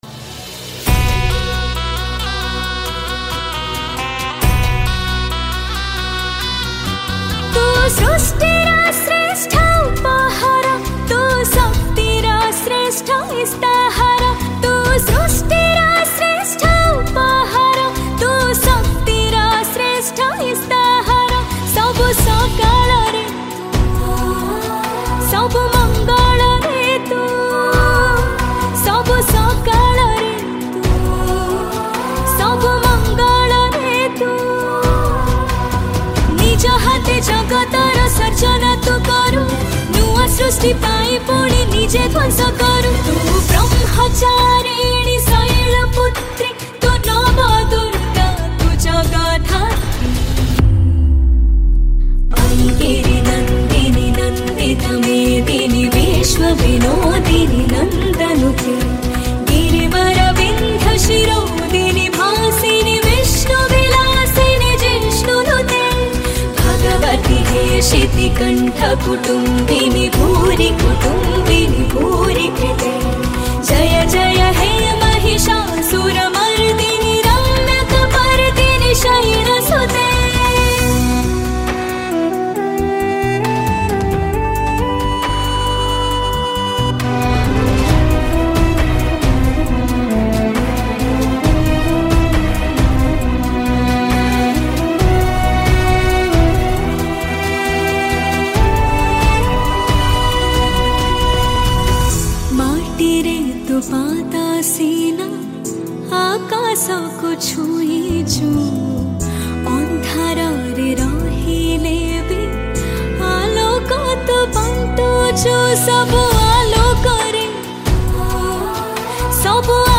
Category: Durga Puja Special Odia Songs